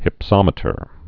(hĭp-sŏmĭ-tər)